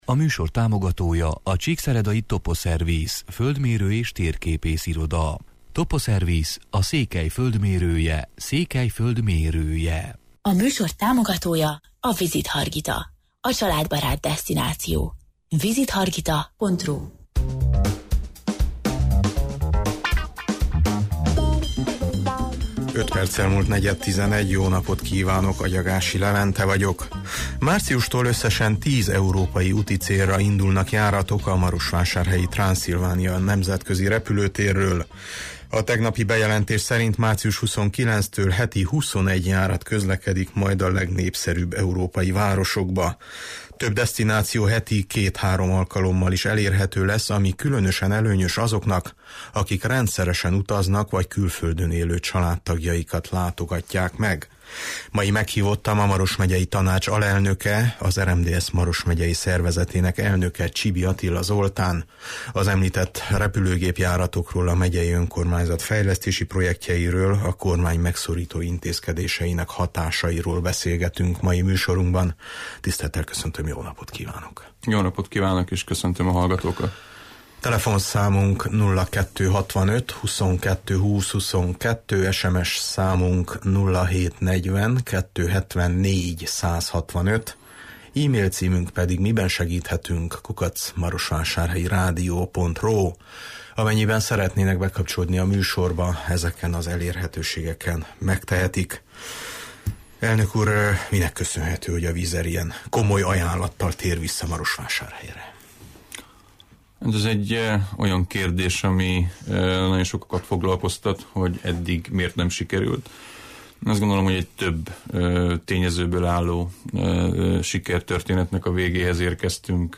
Mai meghívottam a Maros Megyei Tanács alelnöke, az RMDSZ Maros megyei szervezetének elnöke, Csibi Attila Zoltán, akivel az említett repülőgép-járatokról, a megyei önkormányzat fejlesztési projektjeiről, a kormány megszorító intézkedéseinek hatásáról beszélgetünk.